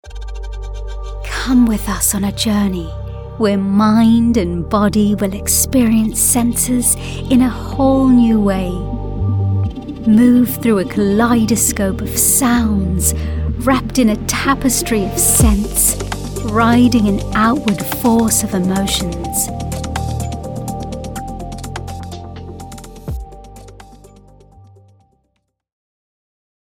Female
Documentary
British Believable Ambient
0326British_Documentary.mp3